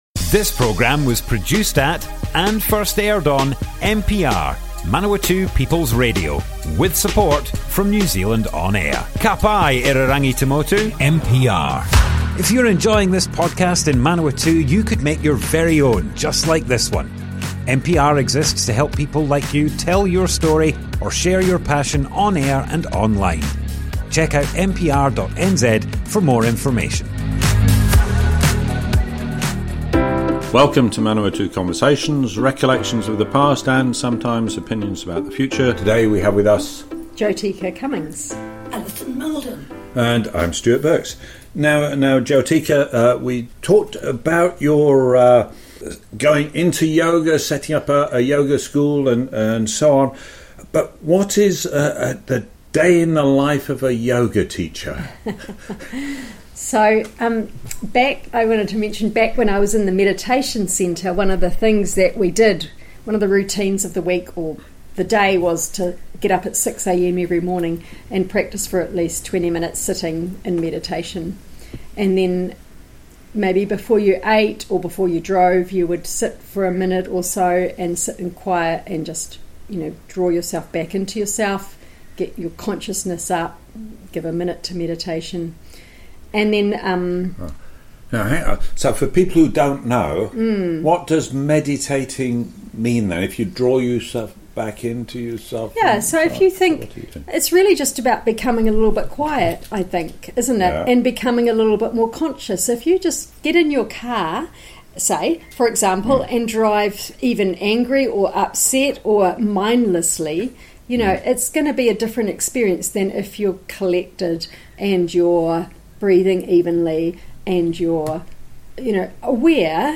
Manawatu Conversations More Info → Description Broadcast on Manawatu People's Radio, 11th November 2025.
oral history